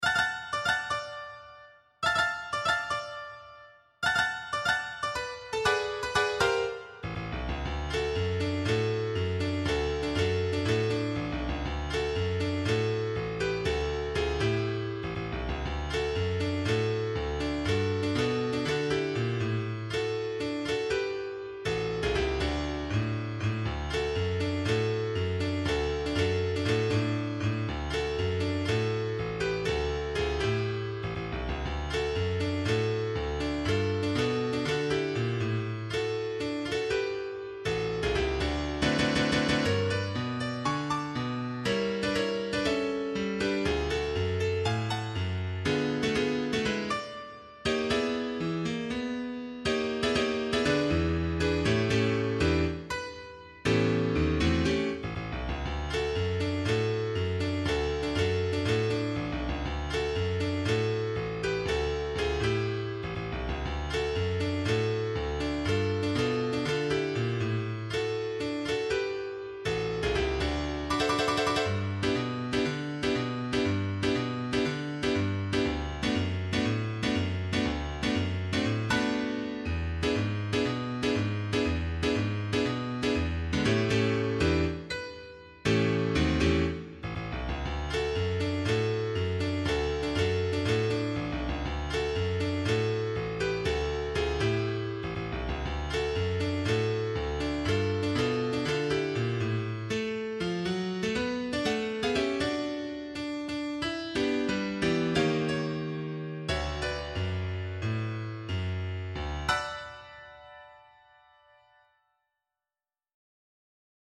Accompaniment Only